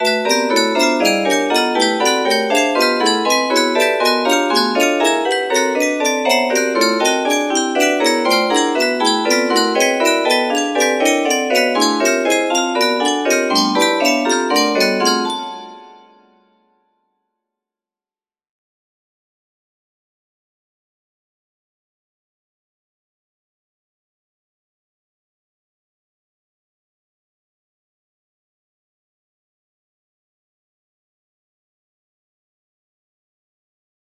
P12 music box melody